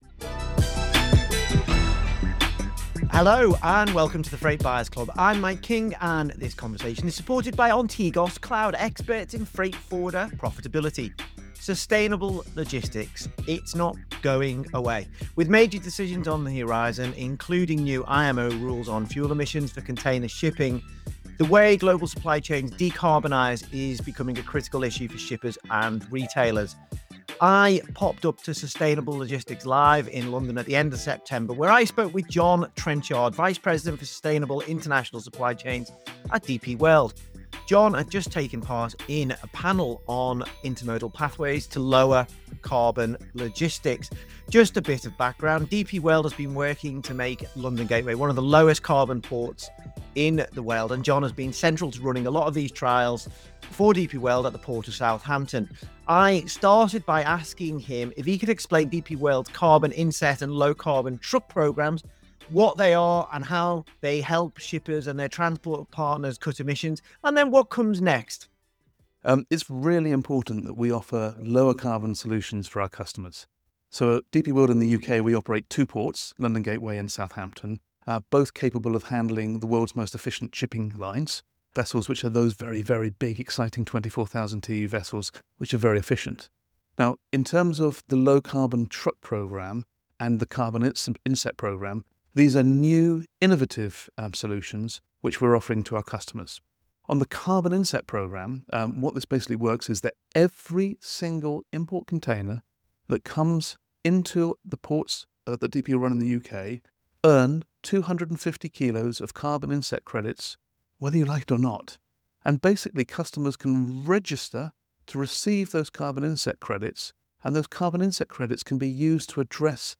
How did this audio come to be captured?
Recorded at Sustainable Logistics LIVE in London, this interview